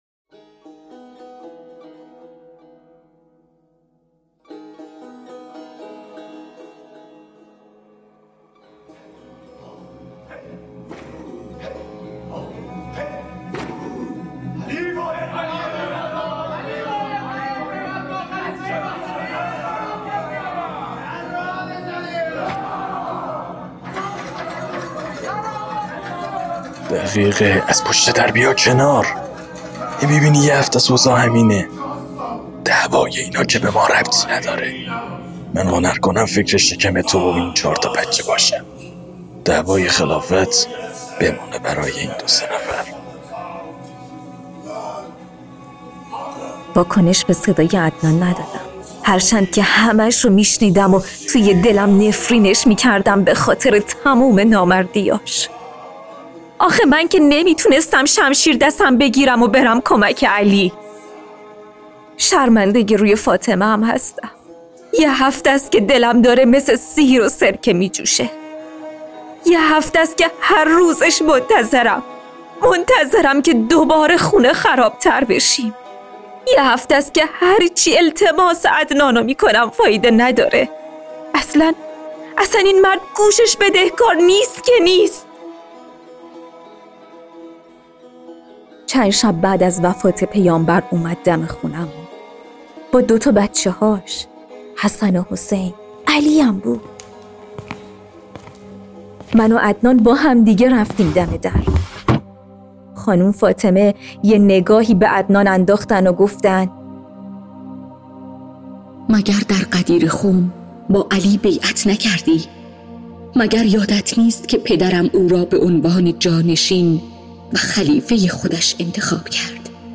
نمایش صوتی یاس در آتش روایتگر بخشی از ماجرای های بعد از پیامبر(ص)از رفتن حضرت زهرا به در خانه انصار و مهاجرین و آتش زدن در خانه حضرت است و در انتها اشاره به حضرت مهدی(عج) دارد در گرفتن حق امامت و ولایت وحضرت علی(ع)